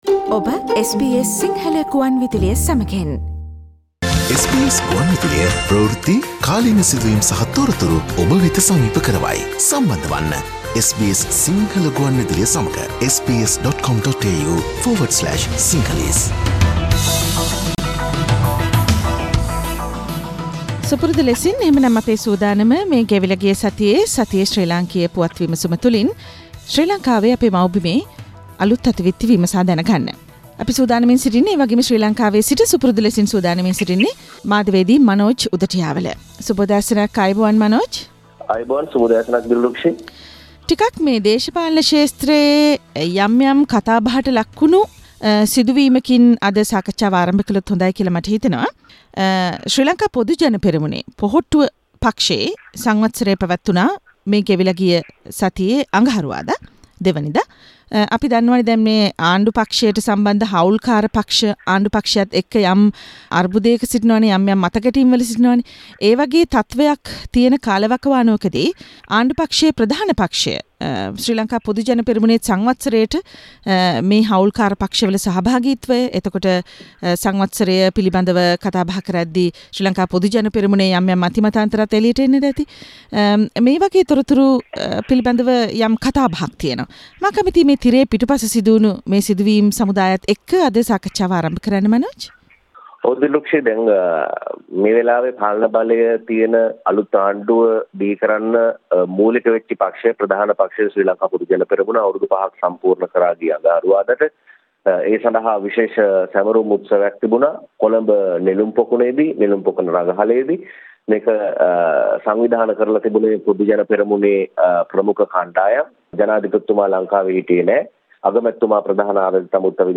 SBS Sinhala radio brings you the most prominent news highlights of Sri Lanka in this featured current affairs segment